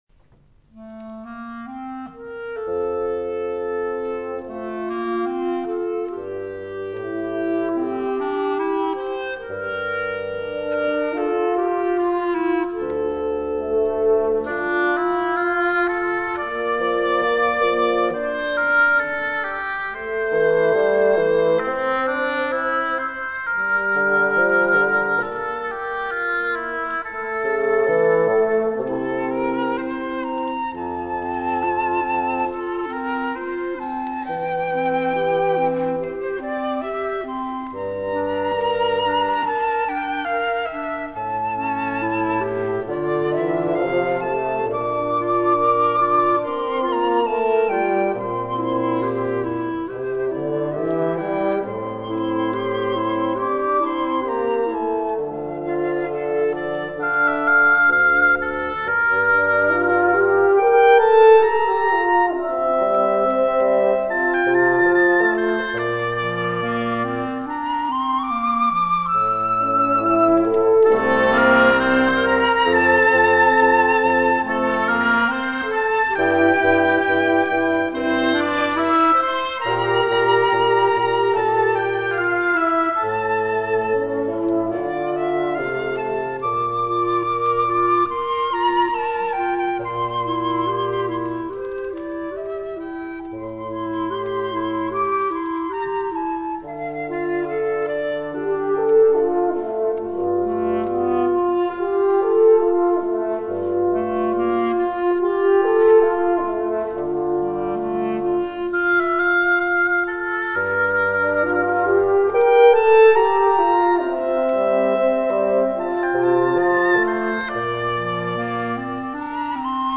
Registrazione live effettuata il 3 Gennaio 2008
nella Chiesa Regina Coeli – AIROLA (BN)